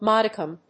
音節mod･i･cum発音記号・読み方mɑ́dɪkəm|mɔ́-
• / mάdəkəm(米国英語)
• / mˈɔdɪkəm(英国英語)